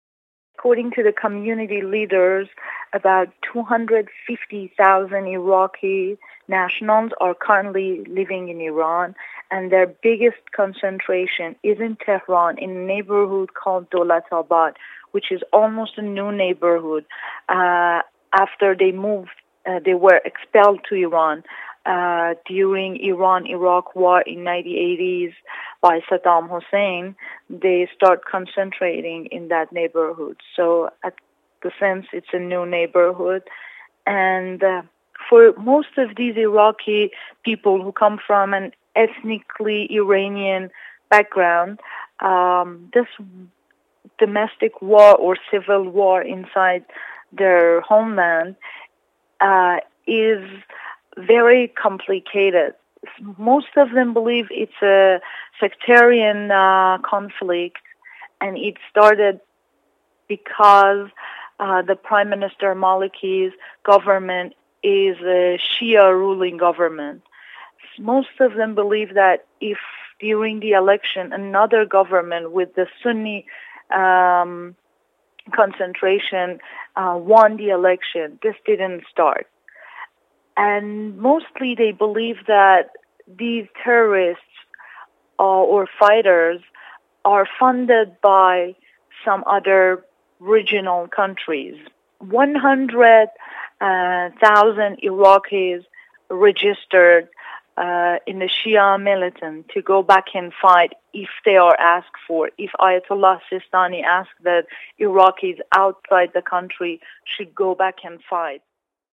attended a demonstration in Tehran were Iraqis gathered to show support for the Shiite-led government in Baghdad.